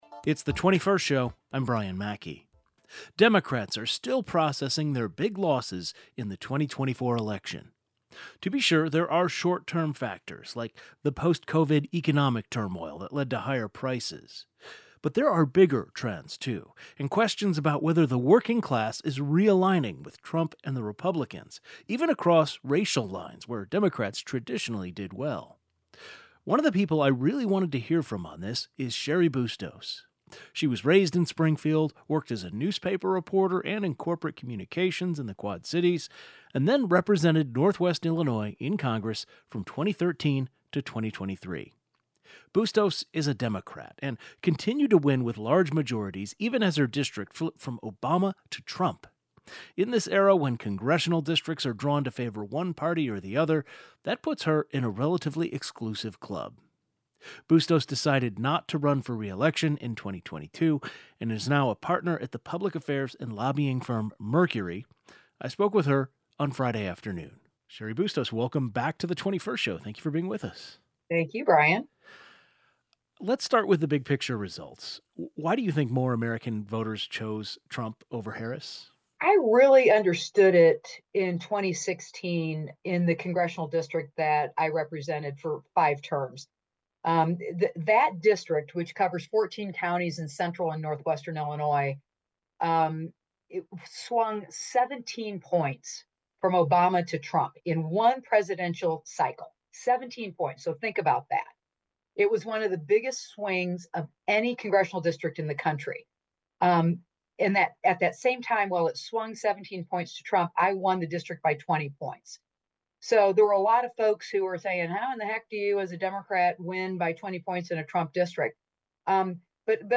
GUEST Cheri Bustos Partner at the public affairs and lobbying firm Mercury Former Democratic congresswoman (Quad Cities and northwest Illinois, 2013–2023) PAR graduate (1985)